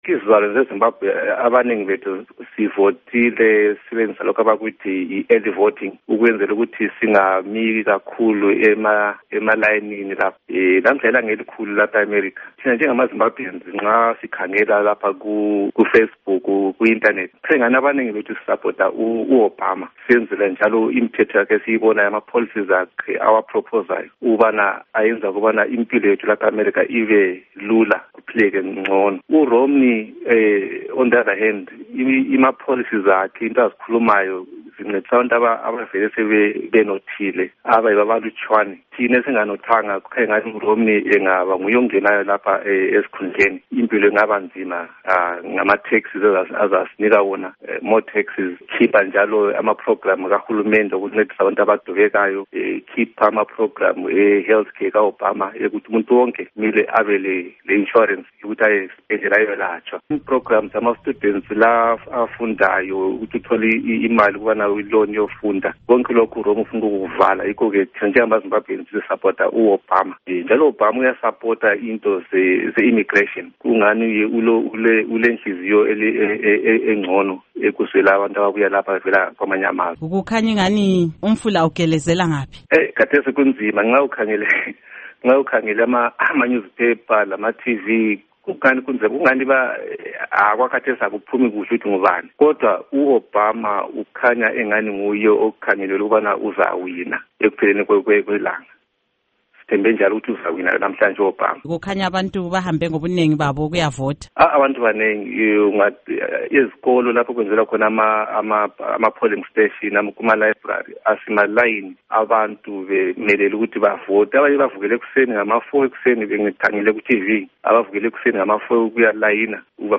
Embed share Ingxoxo LoMnu.